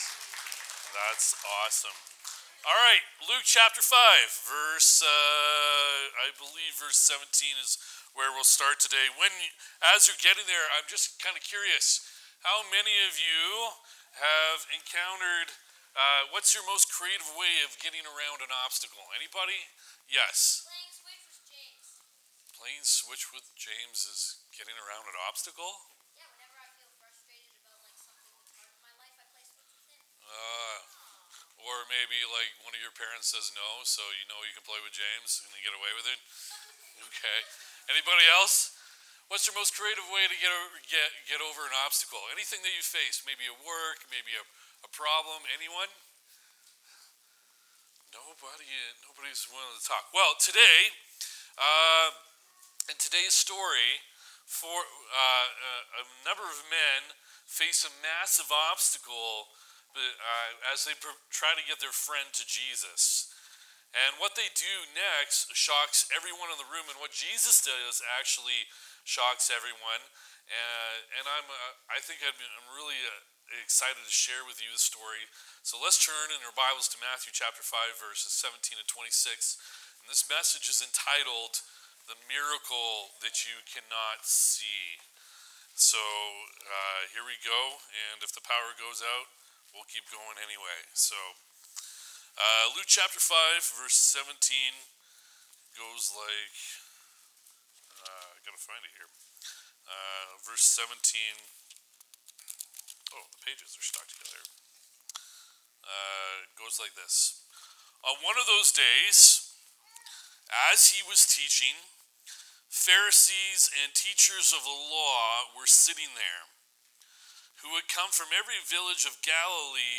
March 8 Sermon